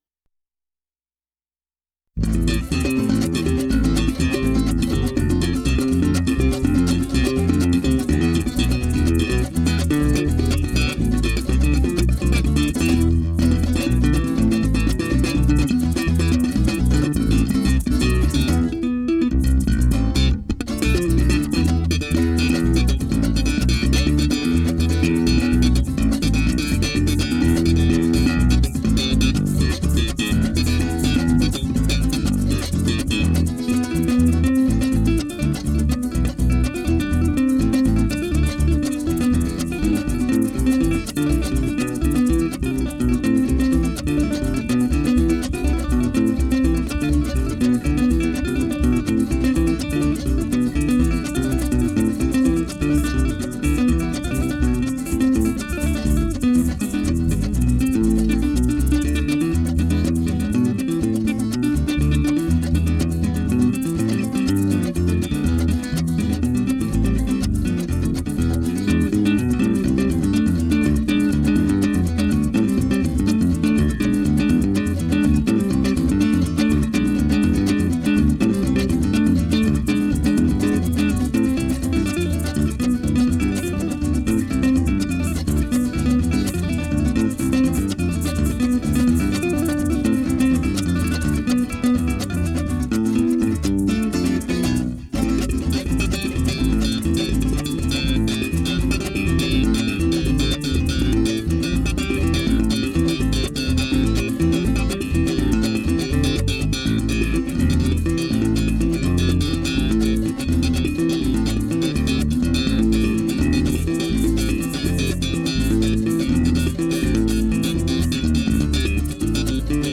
Chite pajarillo - Golpe tradicional .wav (67.36 MB)